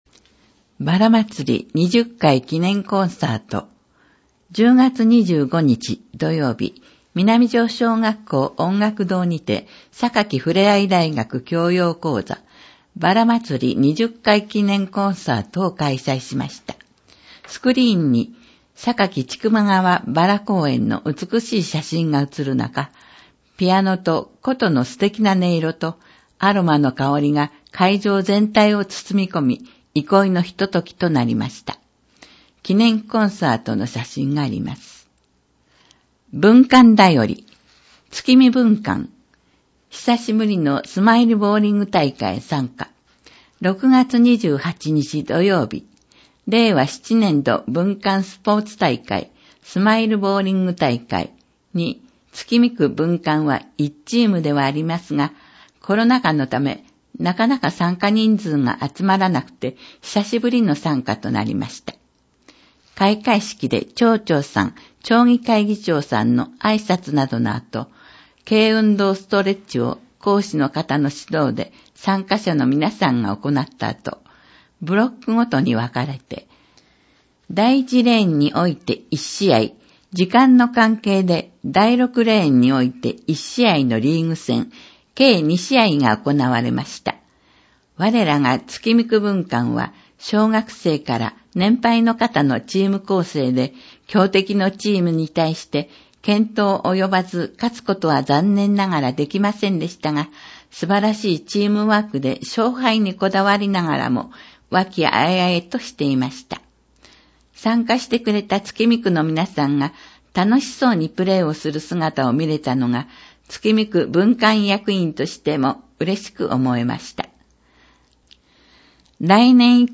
音訳版ダウンロード(制作：おとわの会）